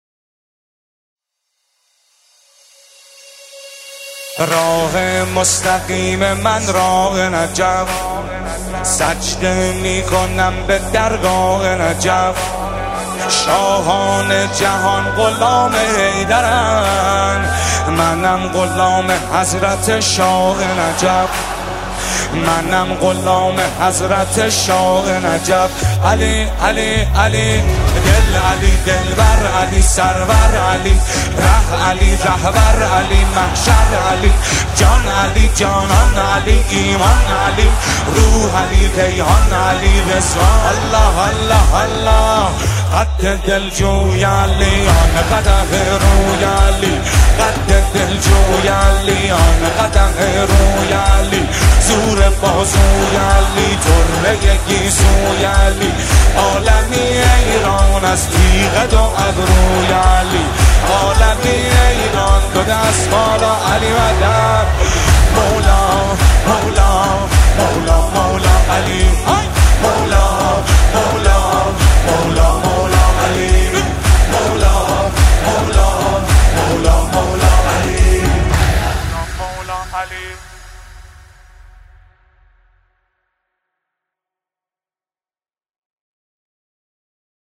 مداحی استودیویی